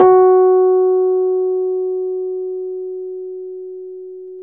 RHODES-F#3.wav